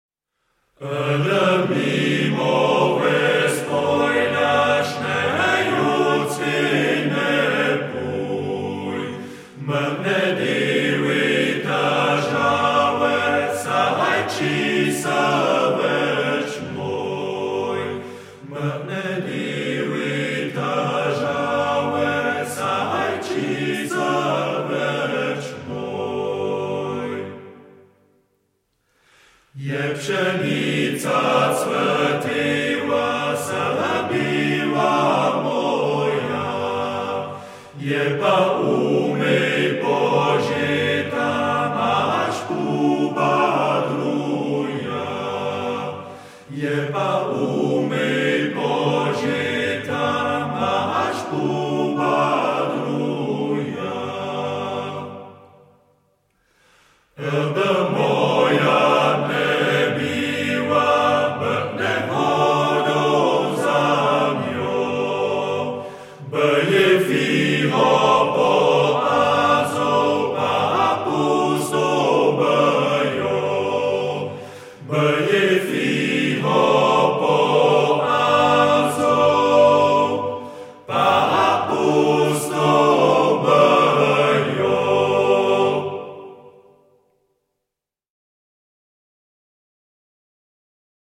koroška narodna